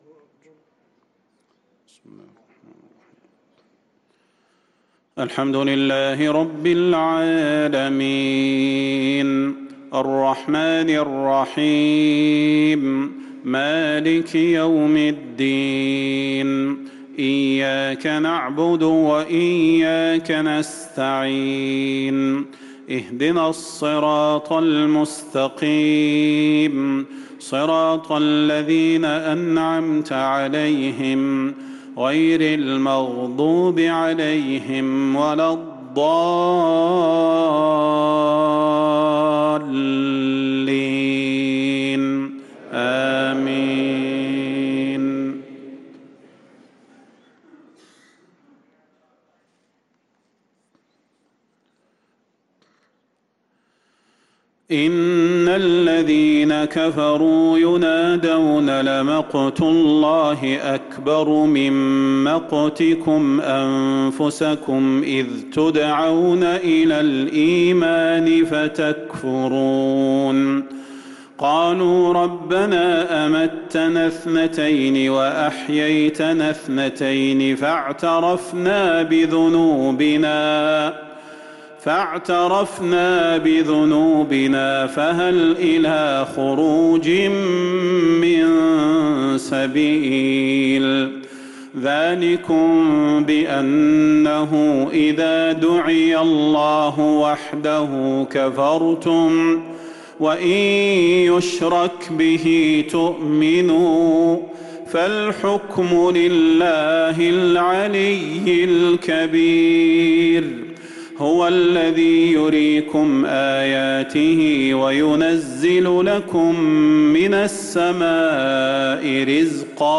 صلاة العشاء للقارئ صلاح البدير 10 رجب 1445 هـ
تِلَاوَات الْحَرَمَيْن .